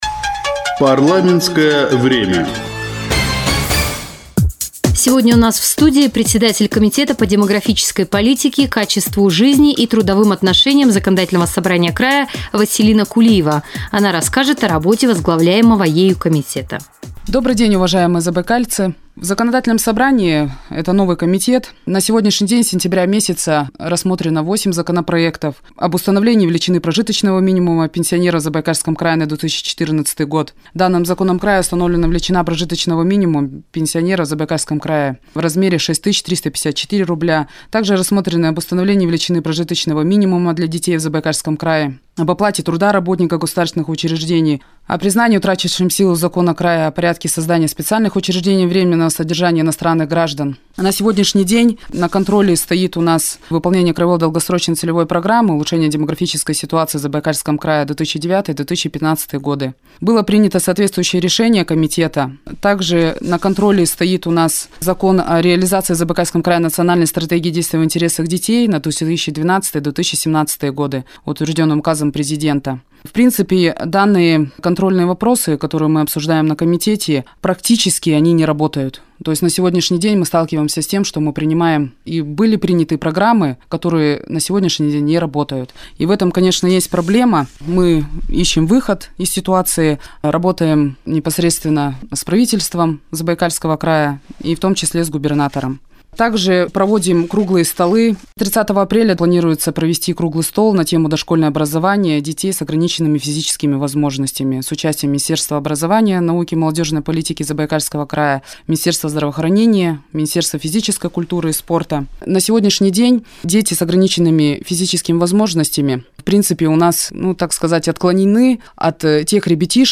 Эфир - "Радио России -Чита" 2 апреля 2014.